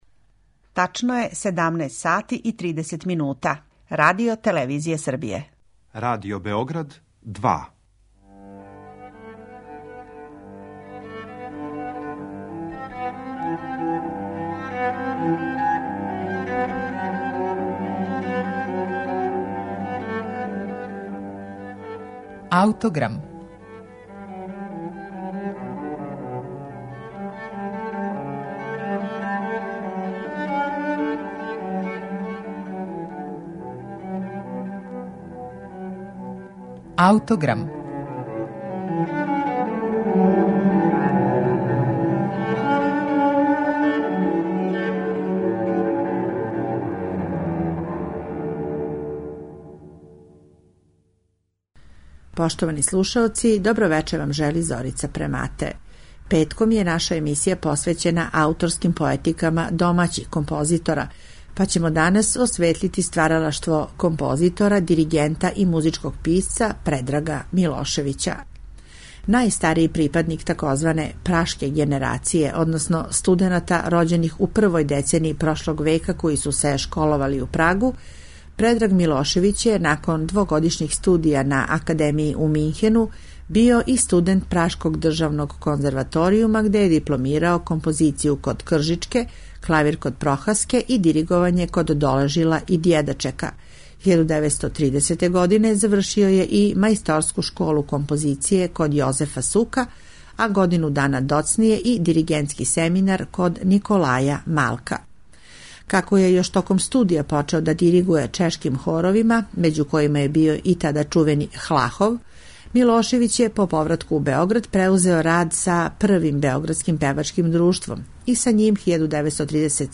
Једно од капиталних остварења српског предратног умереног модернизма, његову Симфонијету, слушаћете са архивског снимка у интерпретацији Симфонијског оркестра Радио-телевизије Београд и диригента Младена Јагушта.